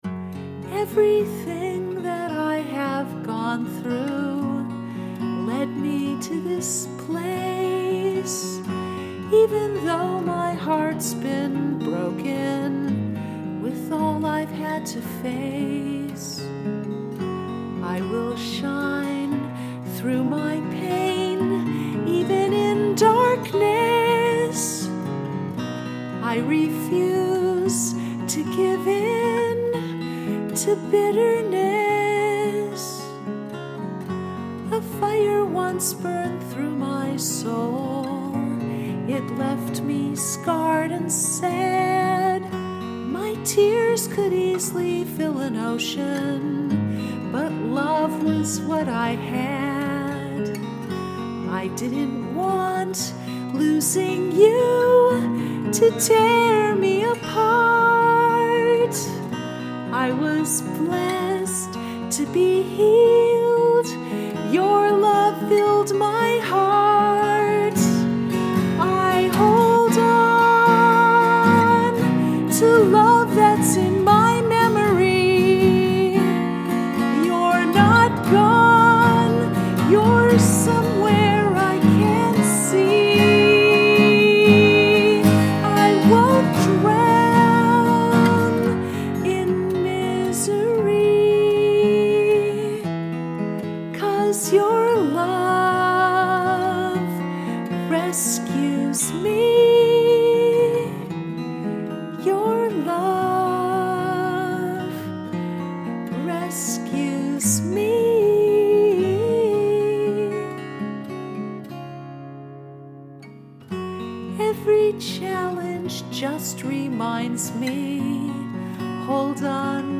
Below is a recent acoustic recording of my song: